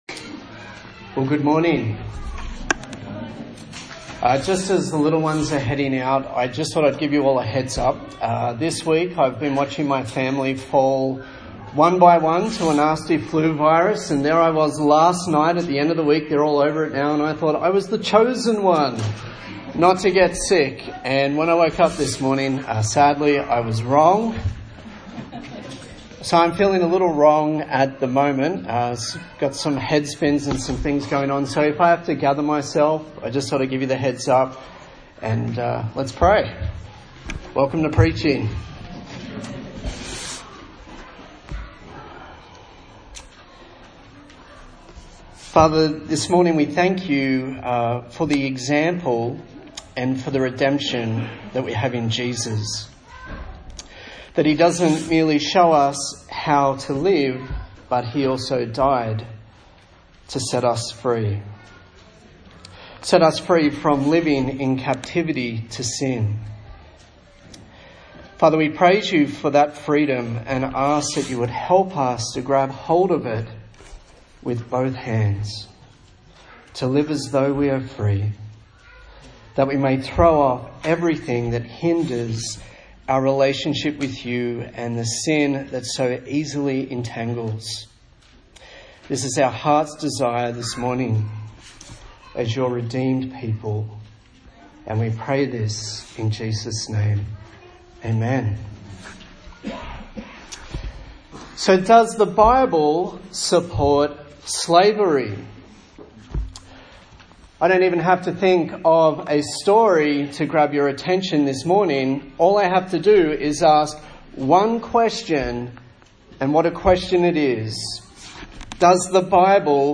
A sermon in the series on the book of 1 Peter
Service Type: Sunday Morning